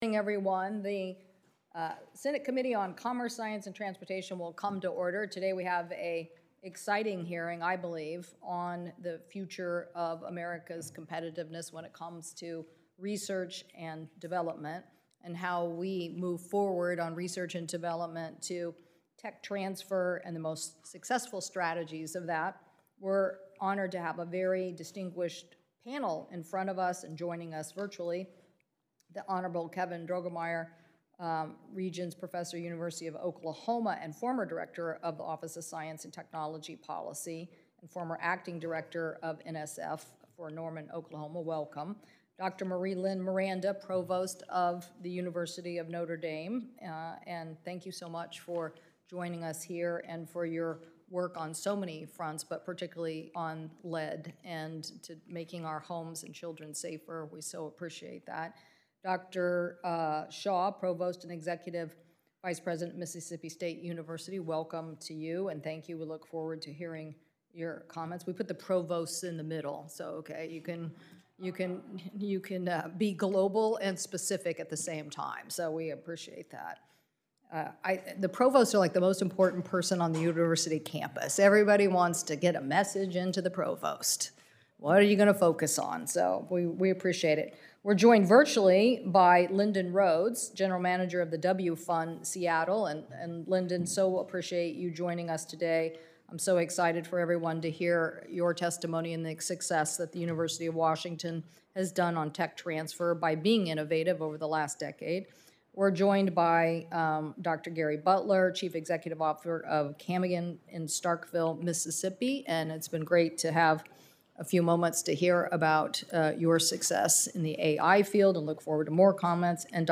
Opening Statement Video |
WASHINGTON, D.C.— U.S. Senator Maria Cantwell (D-WA), Chair of the Senate Committee on Commerce, Science, and Transportation, today held a hearing on the “Endless Frontier Act” which seeks to enhance American competitiveness in the area of research and development.